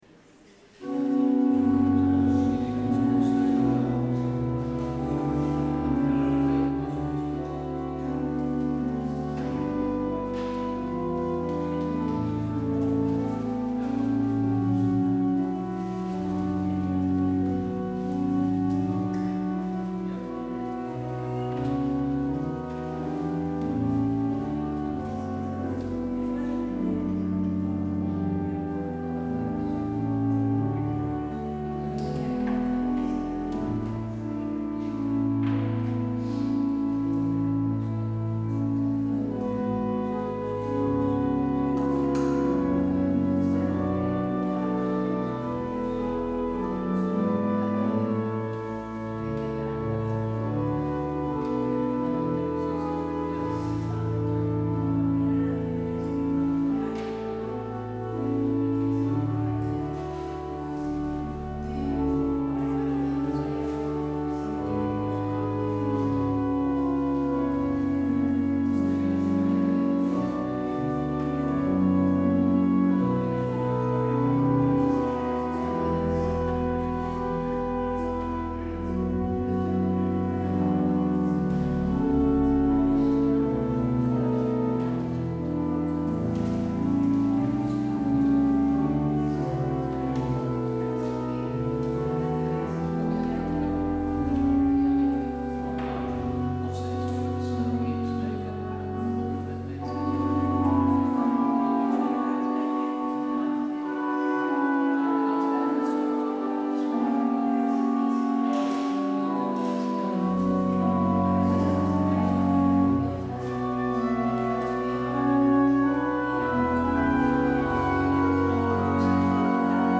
 Luister deze kerkdienst terug: Alle-Dag-Kerk 5 juli 2022 Alle-Dag-Kerk https
Het openingslied is Lied 221 (Zo vriendelijk en veilig als het licht). Het slotlied Lied 416 (Ga met God).